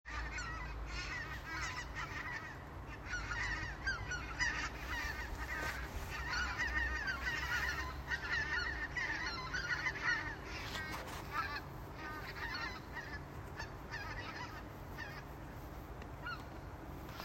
Baltpieres zoss, Anser albifrons
Administratīvā teritorijaĀdažu novads